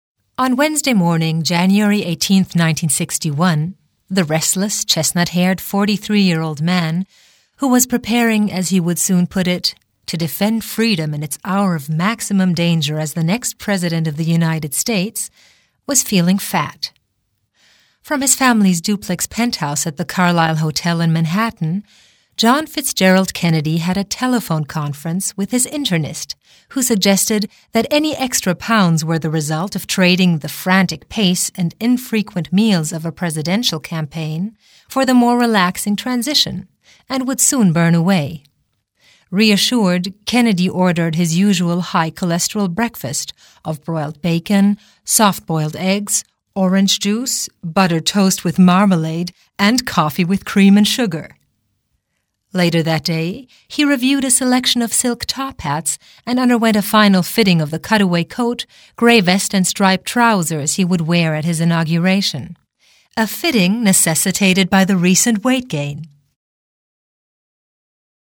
middle west
Sprechprobe: Werbung (Muttersprache):
Native speaker in English (U.S.) and German